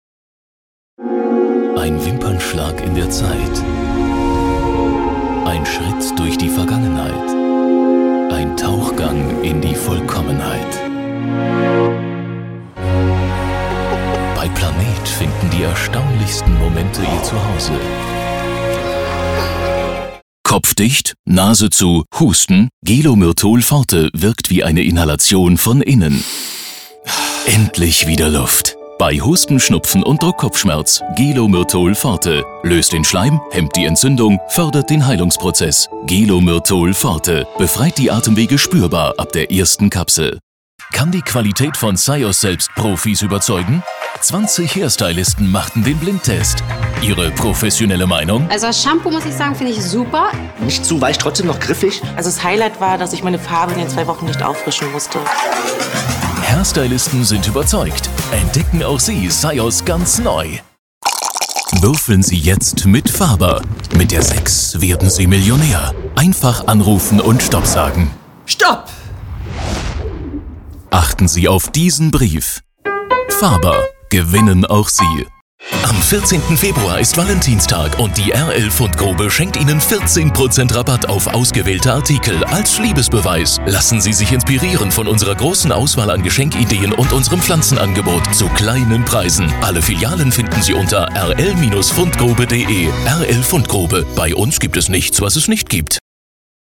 Sprechprobe: Werbung (Muttersprache):
German voice artist for all kinds of commercials, audiobooks, corporate films, e.g. Gelo Myrtol, Ferrero, Porsche Garmin, Henkel, Universal Pictures, Hyundai amm.